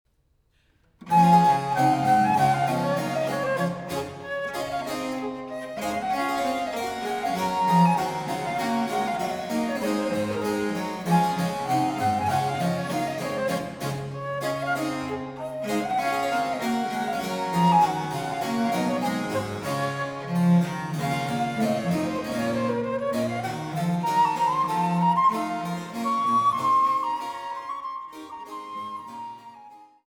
Cantabile